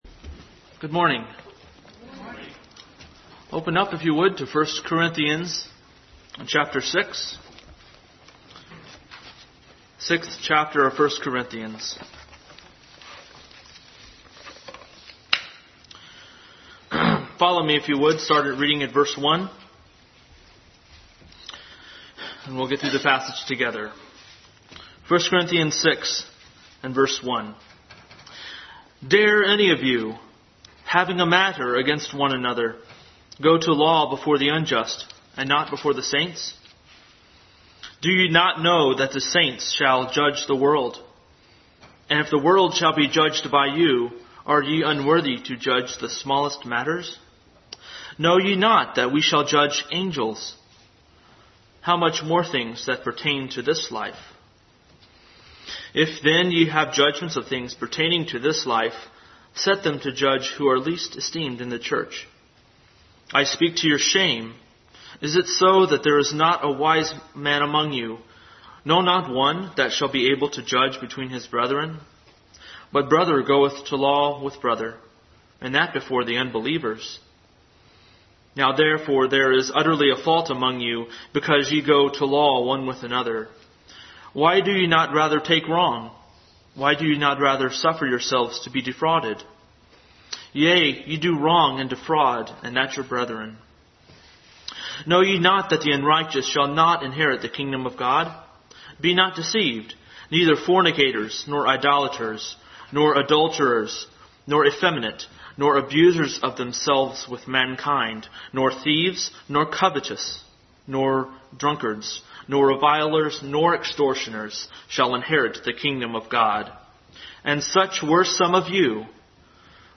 Bible Text: 1 Corinthians 6:1-20, 1 Corinthians 2:12-16 | Adult Sunday School Class continued study in 1 Corinthians.